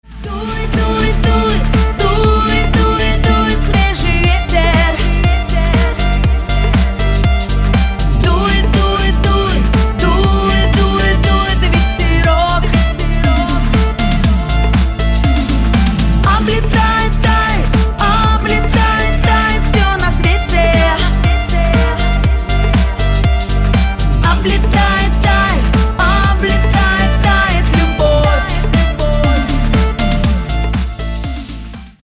громкие
женский вокал
грустные
русский шансон